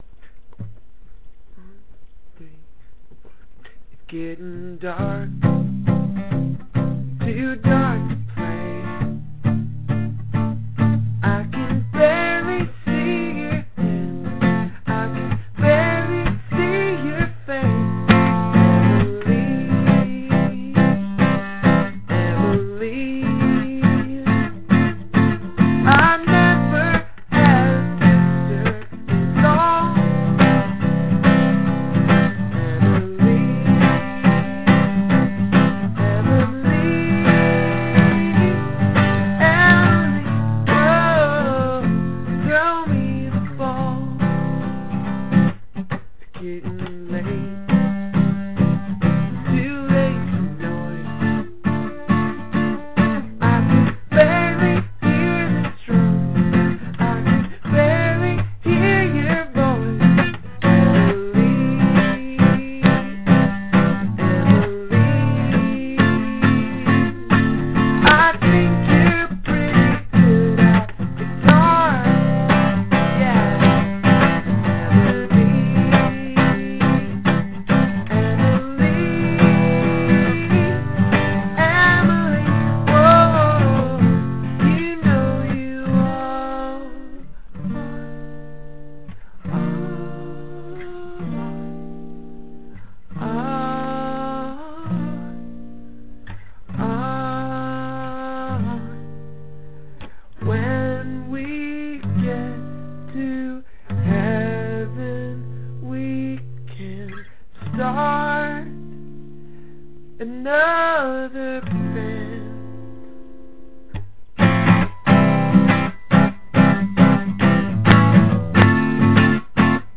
Emily (rough demo) - MP3
this demo is shabby, but i love singing this song so much and i had the little recorder going so i thought i'd get it down for posterity.
work in progress. started all power-pop a la matthew sweet, but then i got this beach boys comp and had to rethink it a bit in lieu of that new evidence.
Emily_(rough_demo).mp3